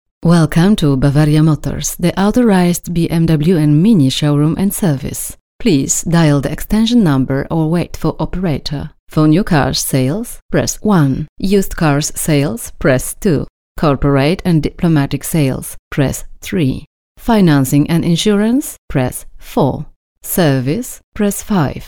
Próbka: Nagranie lektorskie